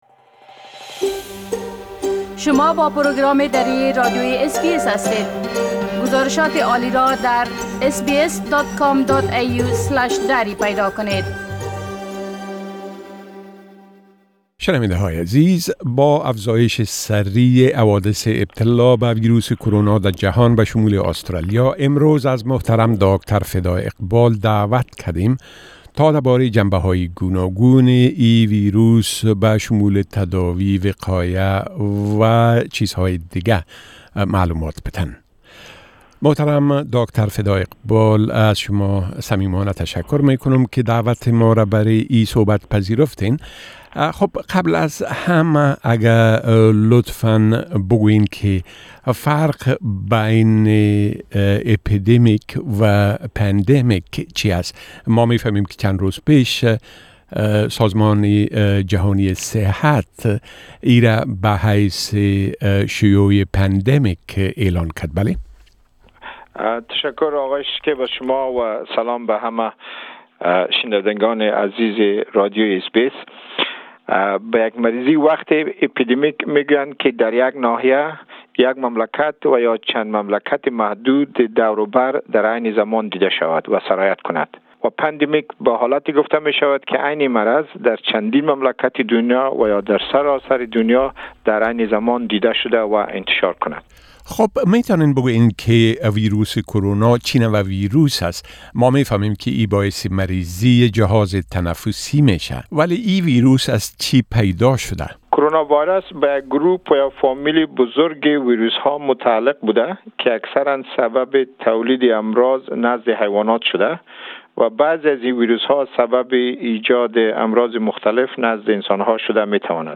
در این مصاحبه: فرق اپیدمی و پاندمی چیست؟